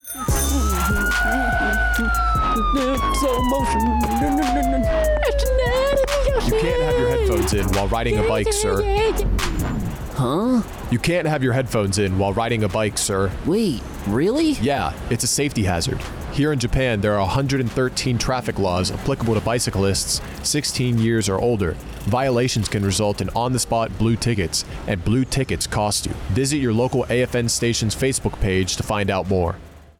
A radio promotion for bicycle law updates affecting servicemembers stationed in Japan, Apr. 17, 2026.